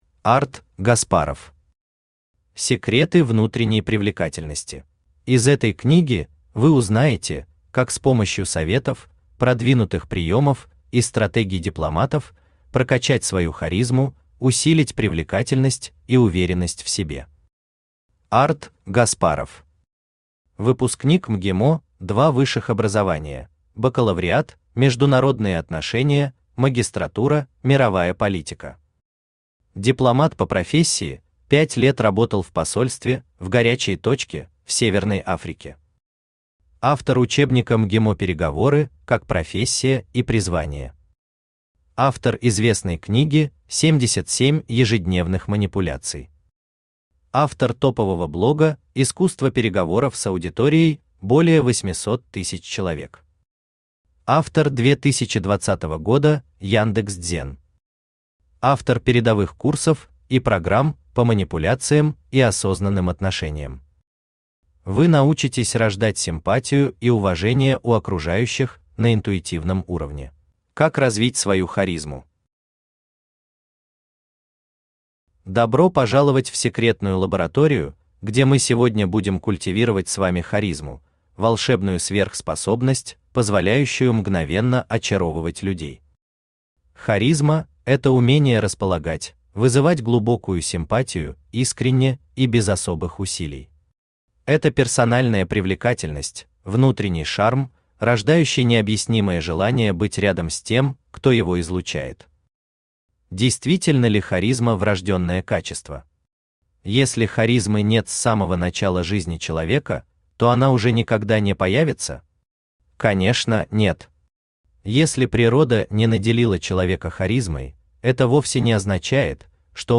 Аудиокнига Секреты внутренней привлекательности | Библиотека аудиокниг
Aудиокнига Секреты внутренней привлекательности Автор Арт Гаспаров Читает аудиокнигу Авточтец ЛитРес.